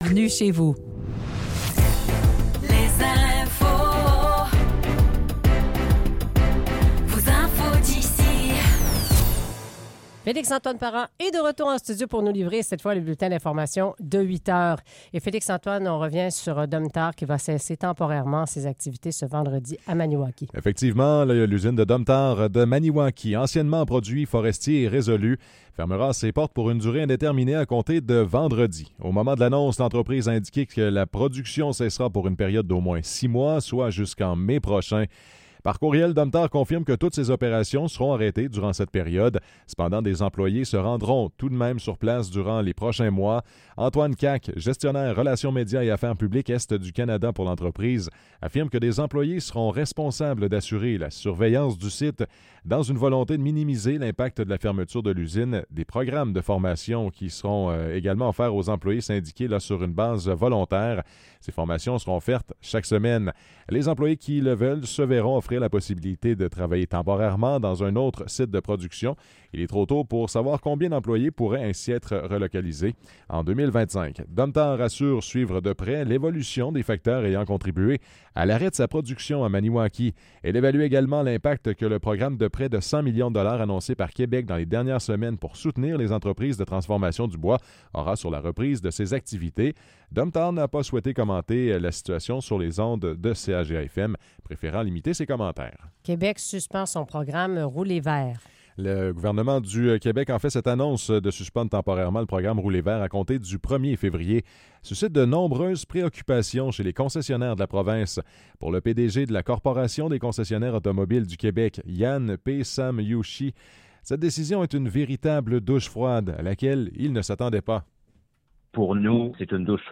Nouvelles locales - 18 décembre 2024 - 8 h